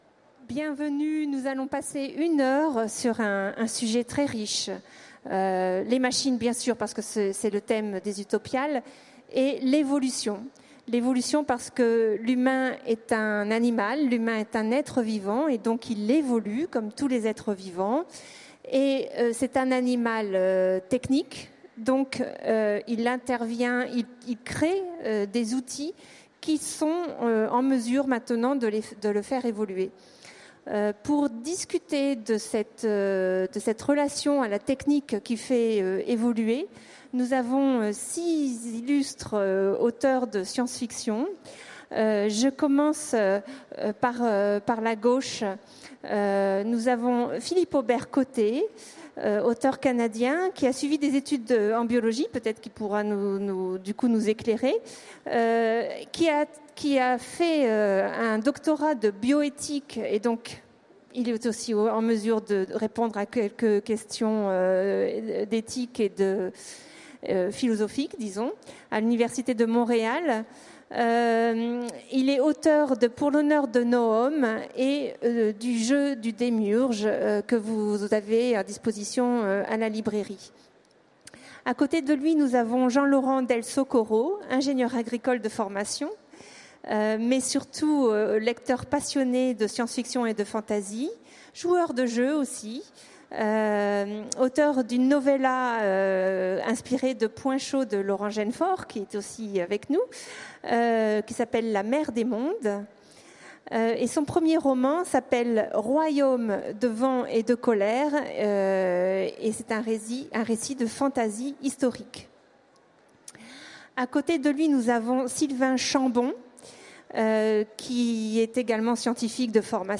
Utopiales 2016 : Conférence La machine à évoluer